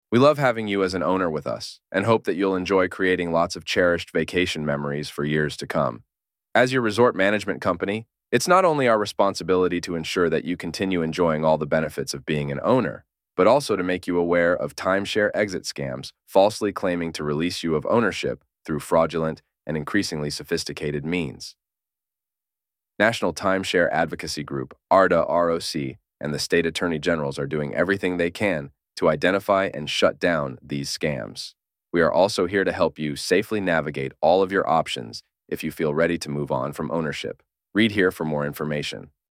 Exit-Smart-Exit-Safe-AI-Voiceover.mp3